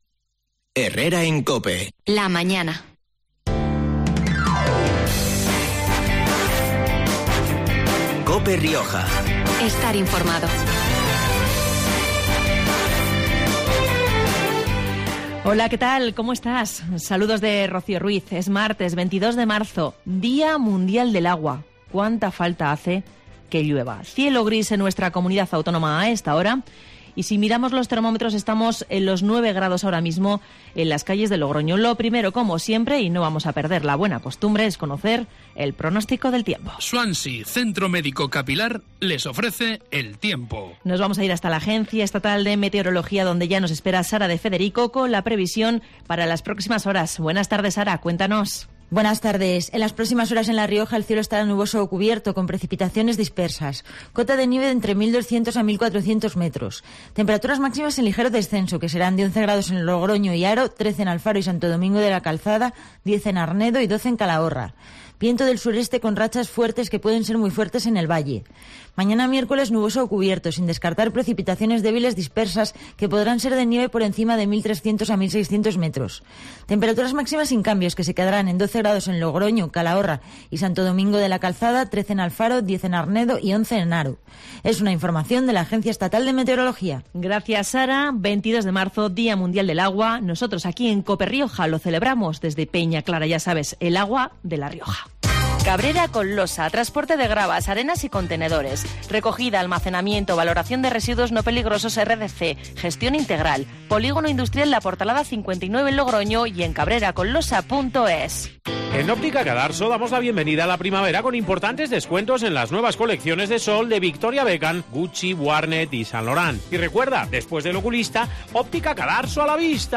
COPE Rioja lleva sus micrófonos a Peñaclara para hablar de los beneficios de su agua embotellada, para que nos cuenten la importancia de la sostenibilidad los recursos hídricos y también para poner en valor el empleo y la riqueza que genera esta empresa en La Rioja.